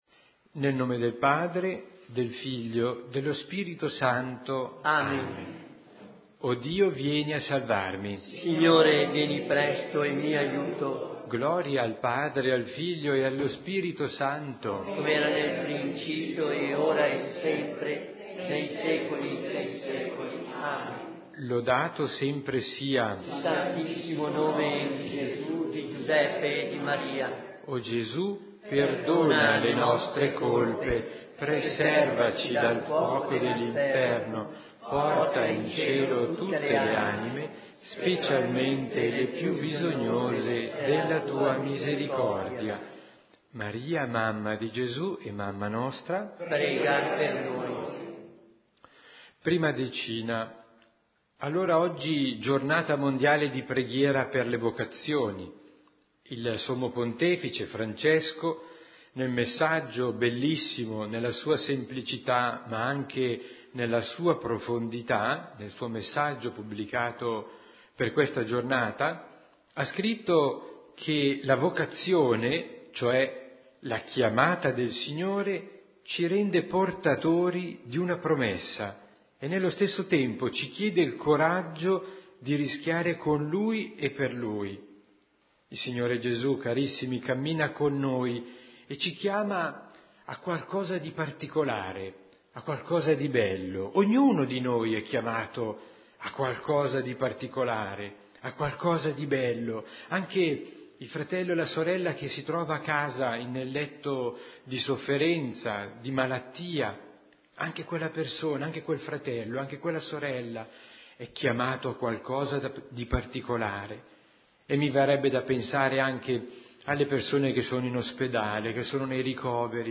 Santo Rosario